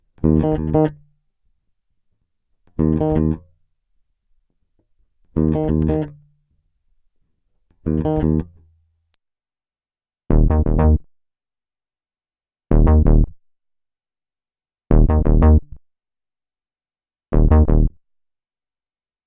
Edit: Chorus Lick hinzugefügt.